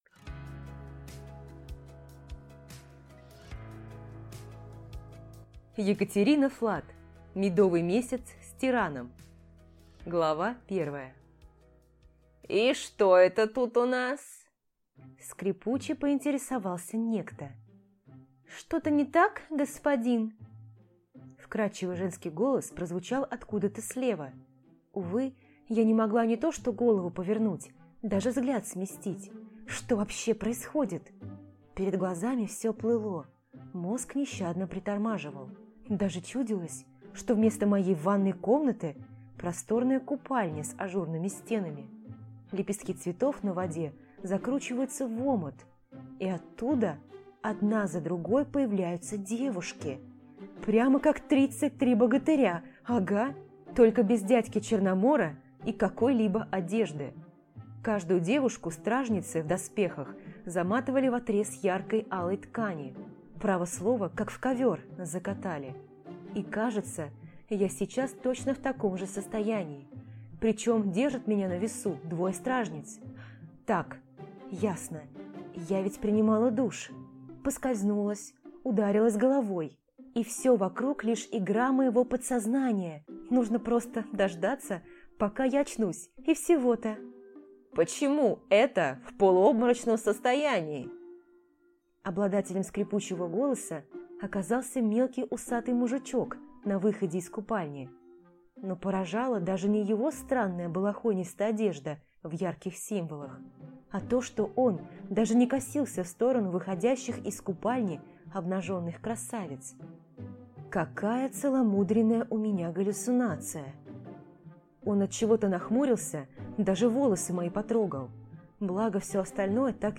Аудиокнига Медовый месяц с тираном | Библиотека аудиокниг